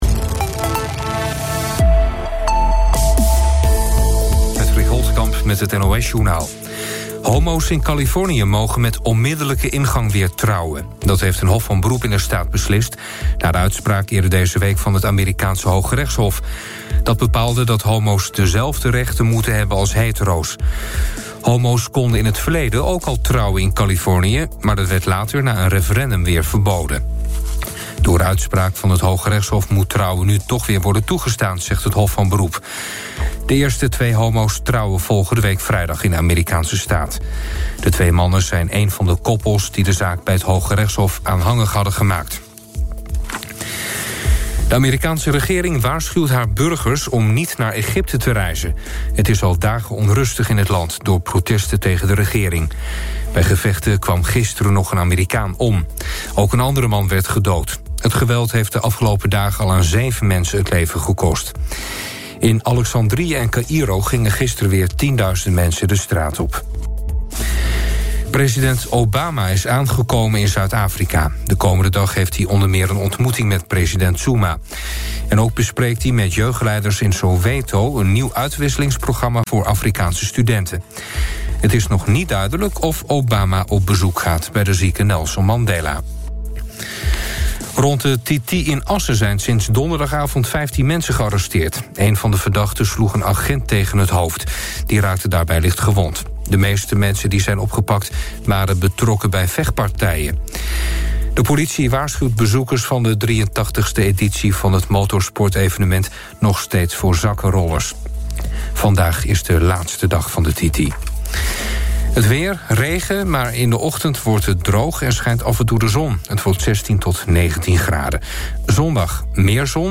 In de nacht van vrijdag op zaterdag was het oude nieuws om 2:00 of 3:00 uur te horen en het heeft weken geduurd voordat iemand het door had.
oudnieuws.mp3